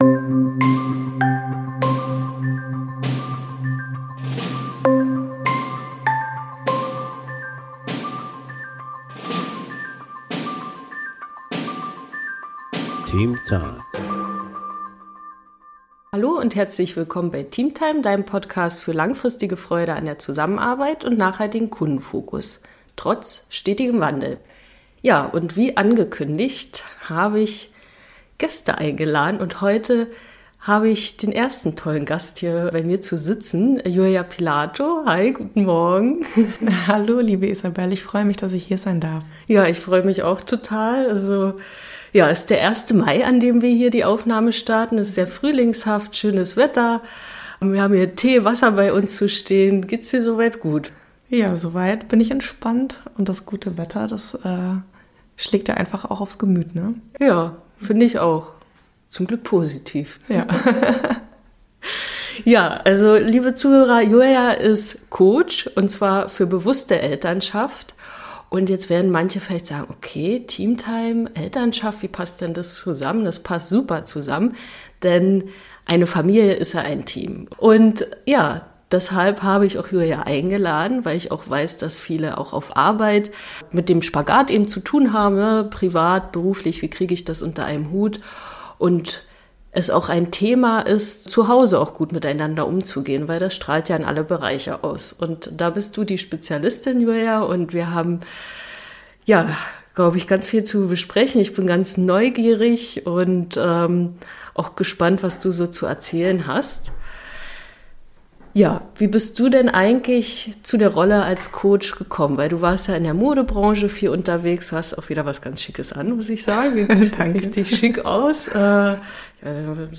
040 Bewusste Elternschaft anstatt Emotionschaos?! - Interview